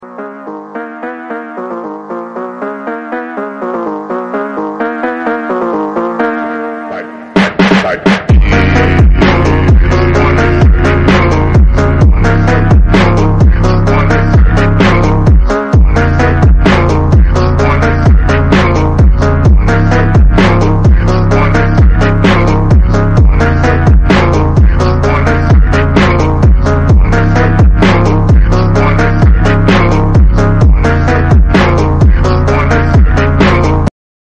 Bro why... sound effects free download